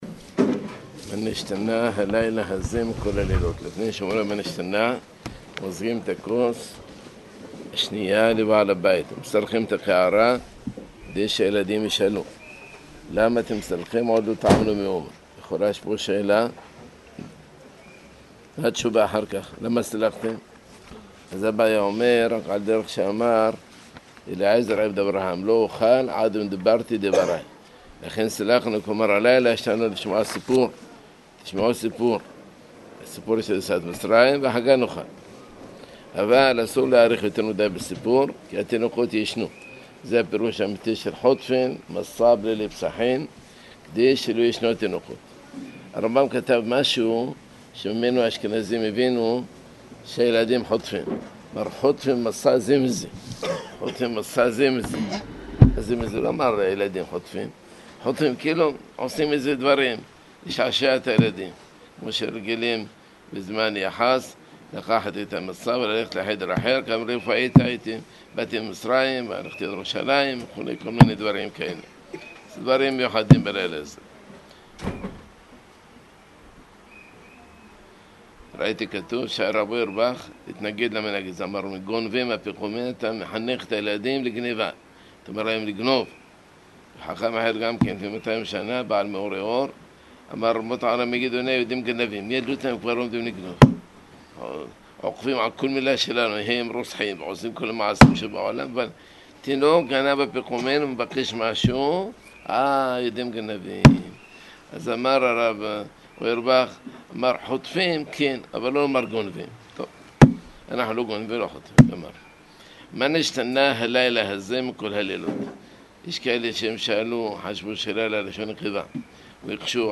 שיעור קצר שנמסר בין מנחה לערבית, בענייני – ההגדה של פסח חלק ב – תש''ע